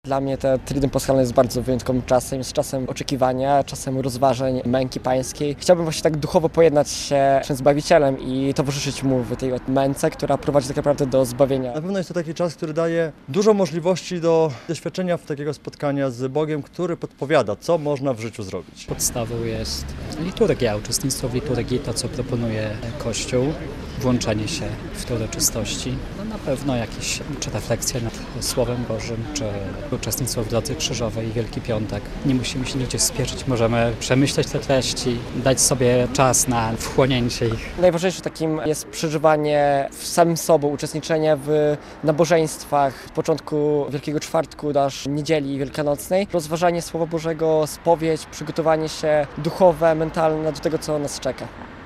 Wielki Czwartek rozpoczyna Triduum Paschalne - relacja